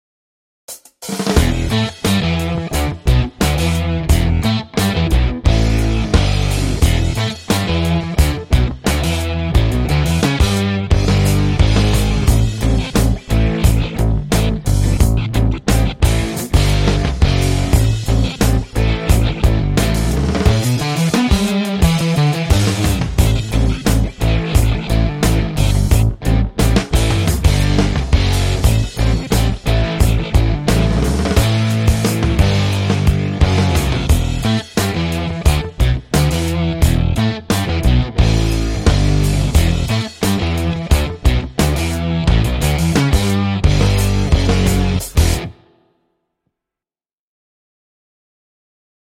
清晰细致的中频，是混音的最佳选择
多种拨片演奏和手掌制音奏法
真正的管箱音色，无可比拟的强大力量
声音类别: 适用于刚劲有力的摇滚以及流行音乐的电贝斯